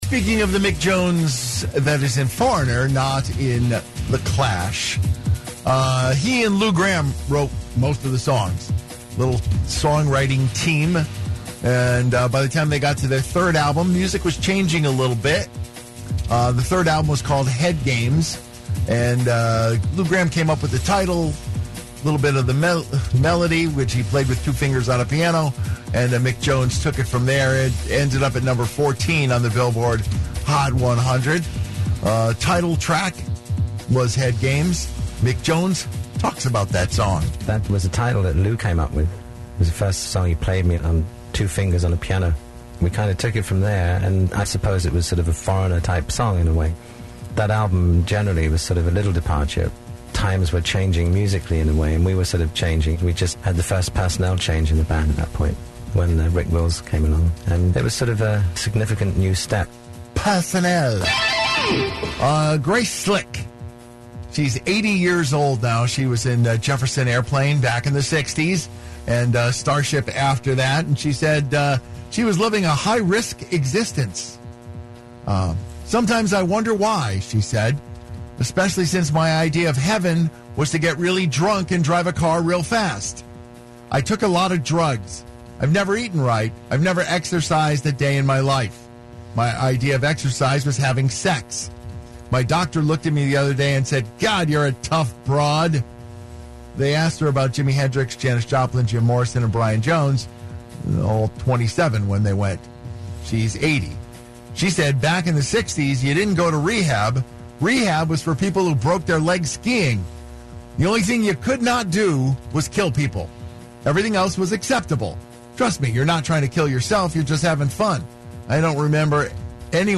It peaked at number-14 on the Billboard Hot 100. here is Mick Jones explaining how it came about and how it fit in with what the band was doing at that time.